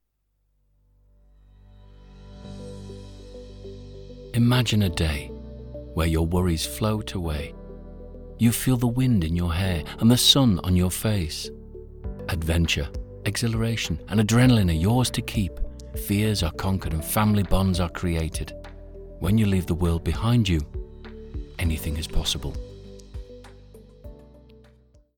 Male
My voice is mature and deep with an authoritative, conversational style. Other characteristics of my voice are believable, articulate and sincere.
Radio Commercials
Holiday Advert